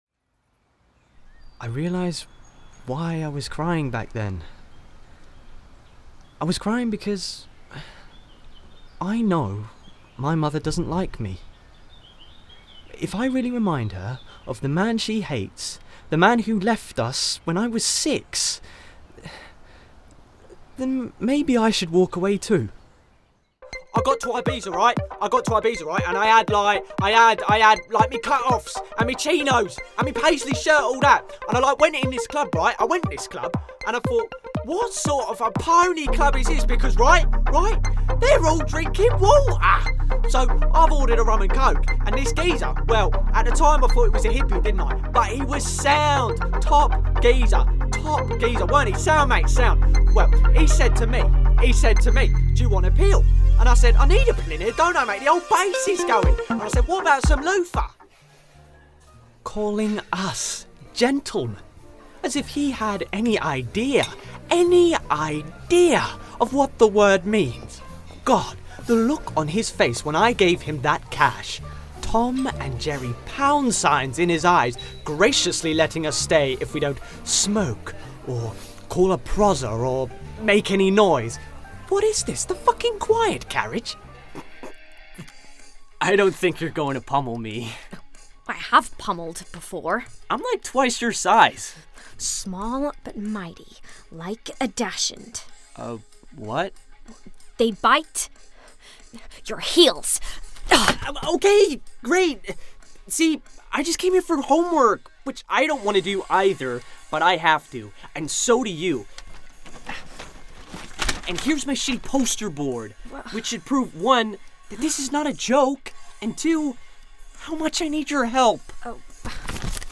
with a warm and youthful sound.
Audio Book/Audio Drama